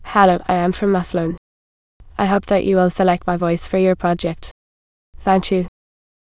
samples/VCTK_p266.wav · voices/VCTK_European_English_Females at 46583eeef89882f8326c93a05c50a25019d6fcc2